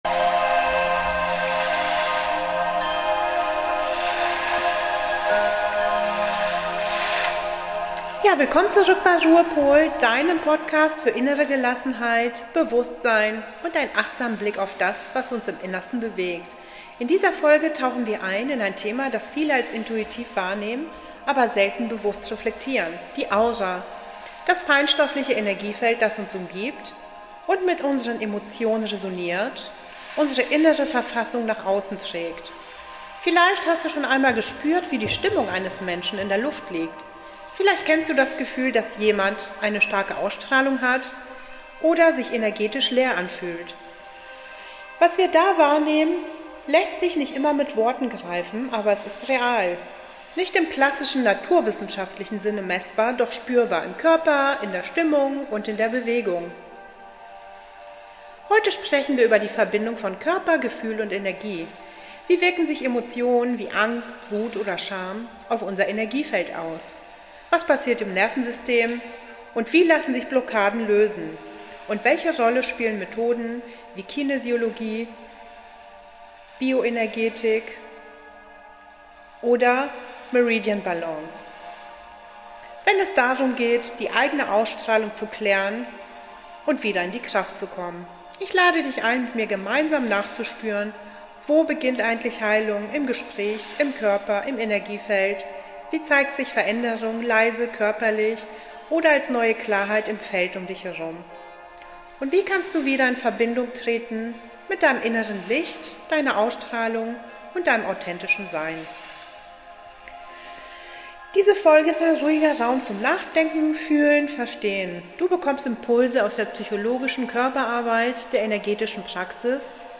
Diese Folge ist ein ruhiger, achtsamer Raum – für alle, die feine Prozesse spüren, verstehen und in Balance bringen möchten.
Meditation: Eine tief entspannende Aura-Meditation, in der du dein Energiefeld bewusst wahrnehmen, reinigen und mit Licht & Schutz aufladen kannst.